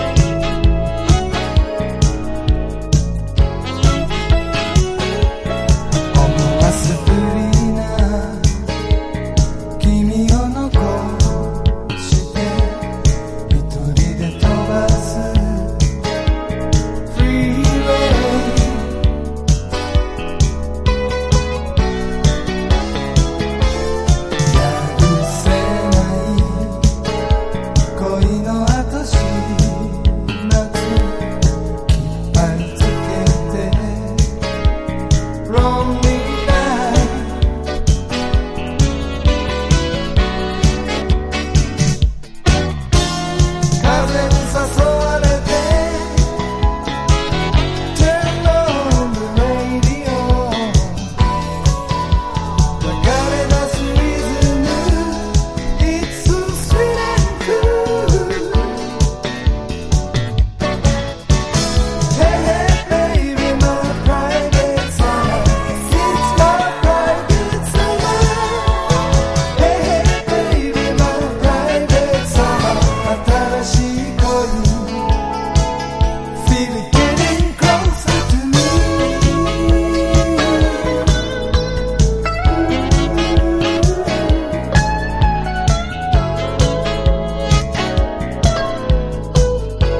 アーバンな空気感のファンク歌謡B3やクールなシティポップナンバーA1等粒揃いです。
CITY POP / AOR